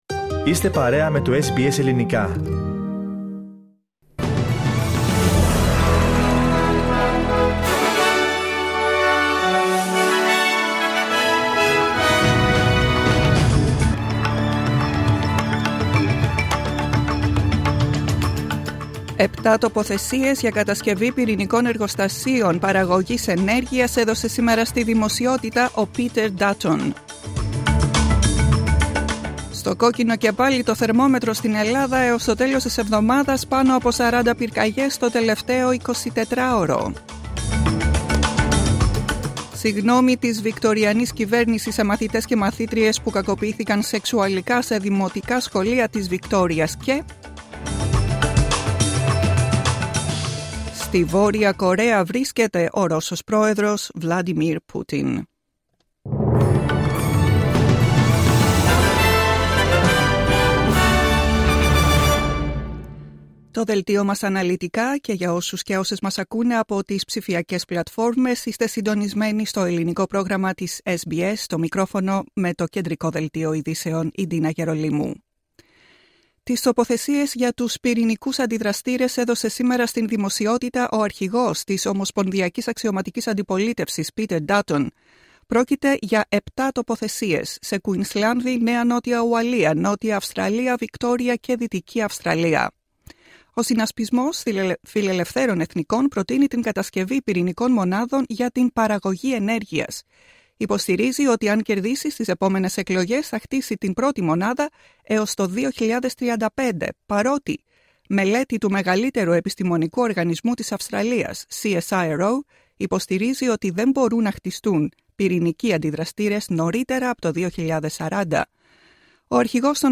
Δελτίο ειδήσεων, Τετάρτη 19 Ιουνίου 2024